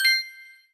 Coins (2).wav